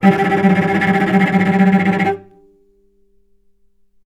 vc_trm-G3-mf.aif